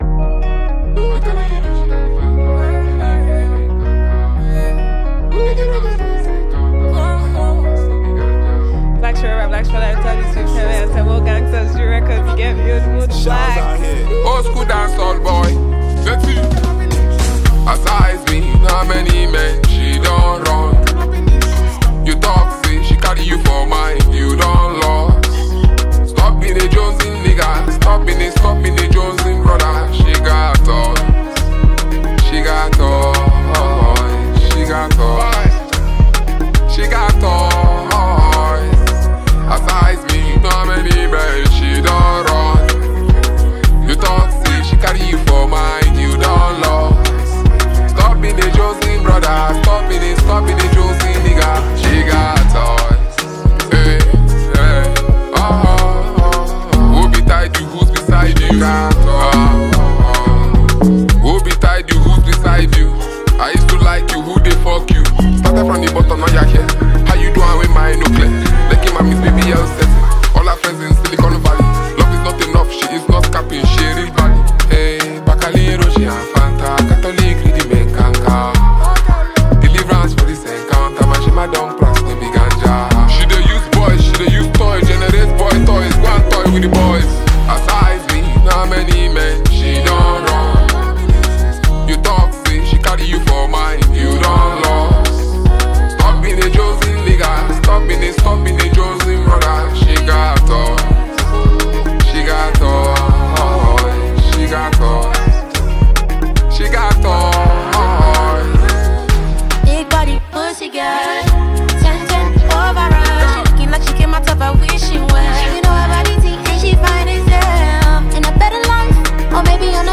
fiery song
Nigerian heavyweight rap maestro and poet